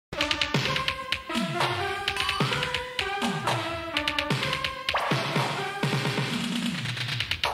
SynthSolo.mp3